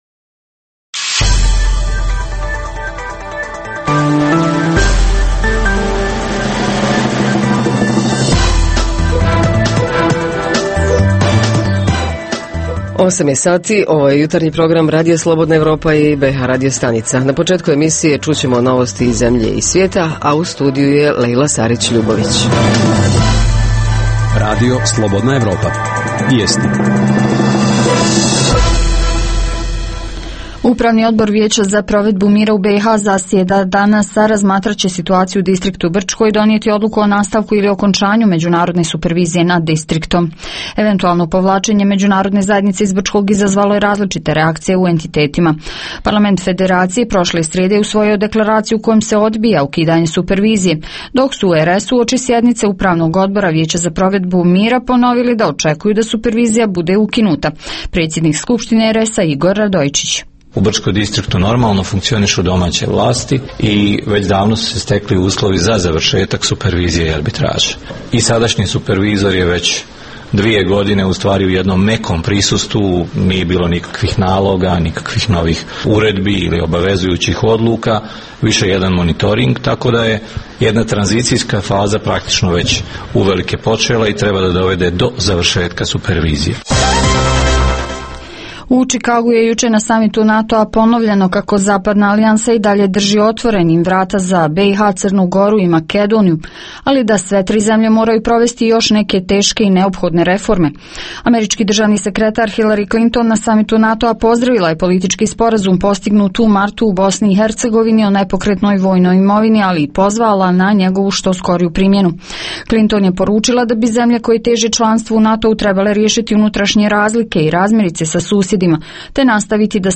Kultura komuniciranja - razvijamo li je u porodici, školama, medijima, javnim inistitucijama, poslu, na ulici? Reporteri iz cijele BiH javljaju o najaktuelnijim događajima u njihovim sredinama.
Redovni sadržaji jutarnjeg programa za BiH su i vijesti i muzika.